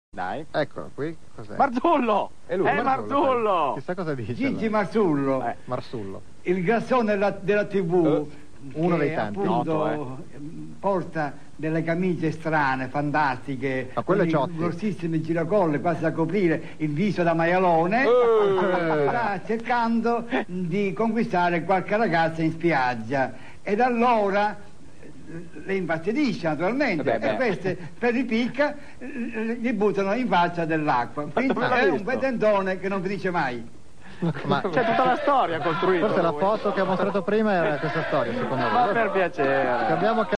Ma ecco alcuni mp3 tratti da "Mai dire TV" della Gialappa's Band: